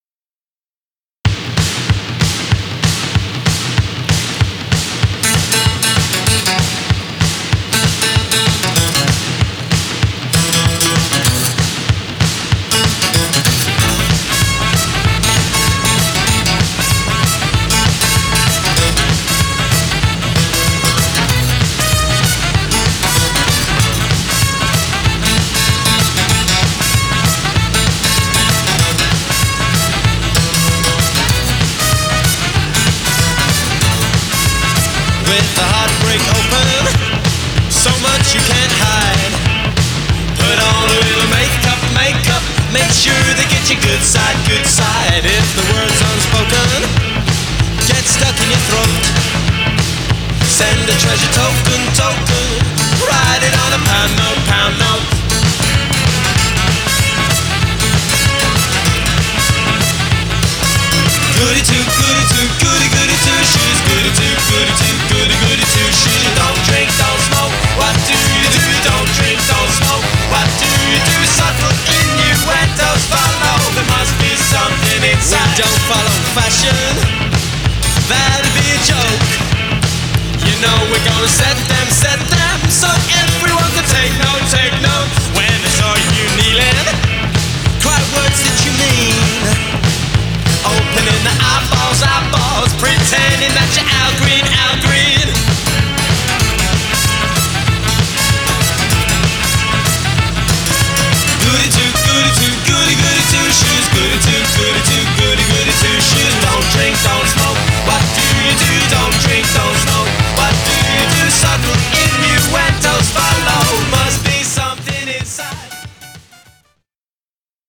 BPM192